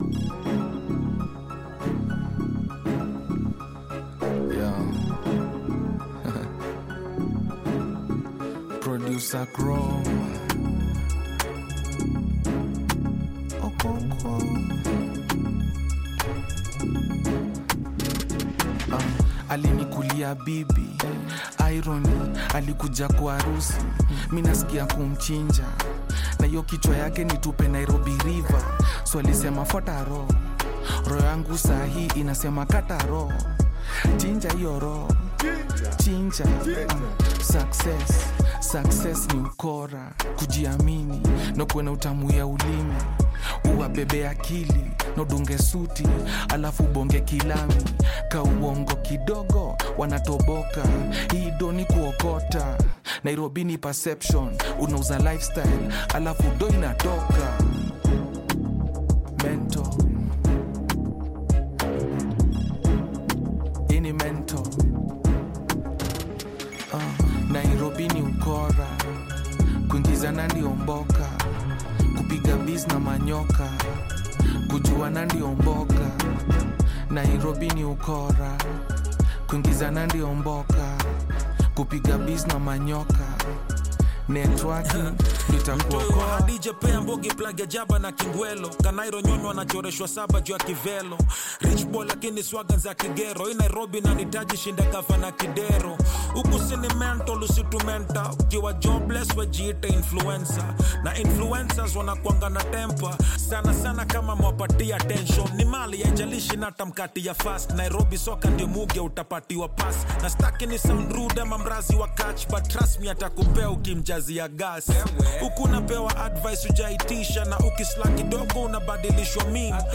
sharp Kenyan hip‑hop/Gengetone collaboration
Hip Hop